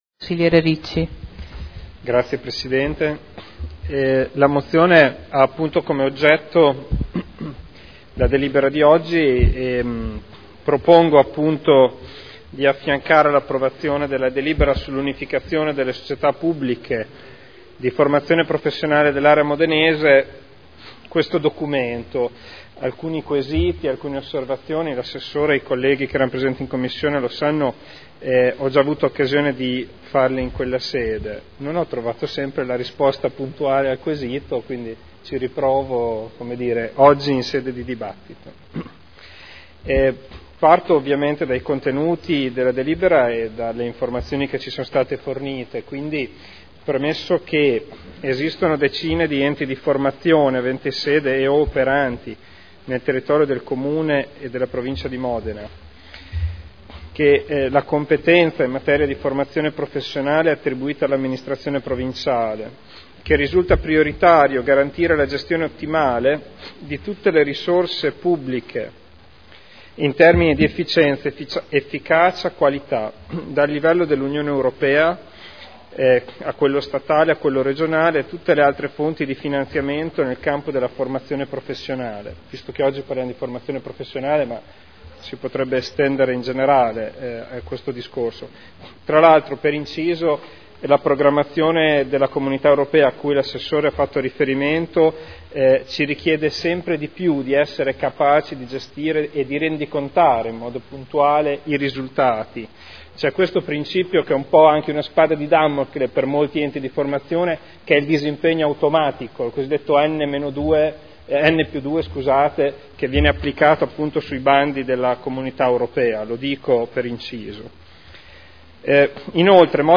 Federico Ricci — Sito Audio Consiglio Comunale
Seduta del 16 aprile. Proposta di deliberazione: Unificazione delle società pubbliche di formazione professionale dell’area modenese.